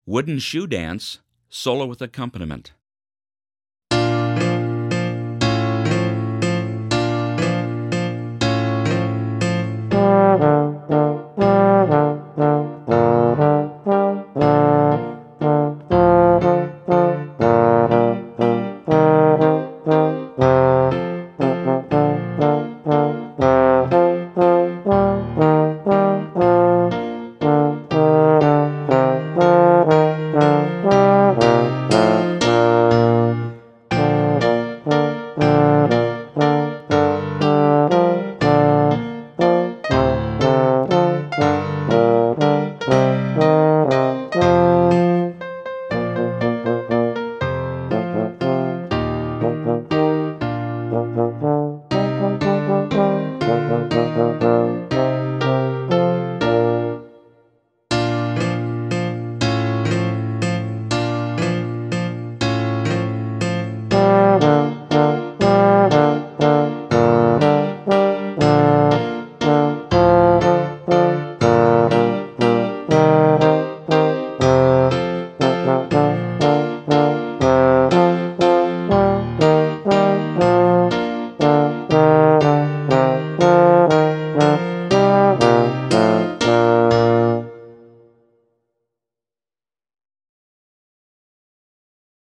Solo with Piano Accompaniment – Performance Tempo
Trombone-Wooden-Shoe-Dance-Solo-With-Accompaniment.mp3